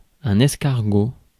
Ääntäminen
Synonyymit limaçon Ääntäminen France: IPA: [ɛs.kaʁ.ɡo] Haettu sana löytyi näillä lähdekielillä: ranska Käännös Konteksti Substantiivit 1. tigu ruoanlaitto Suku: m .